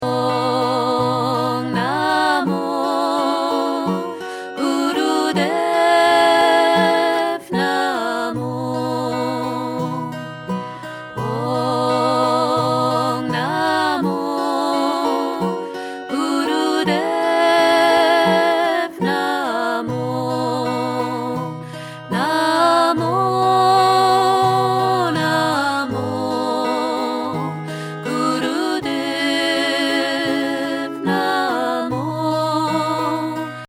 Sikh-Mantra